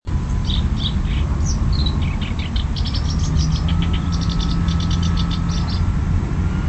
弱いさえずりではありませんでしたが特徴のある節回しですぐわかりました。
コヨシキリのさえずり～２０１４年５月３０日録音。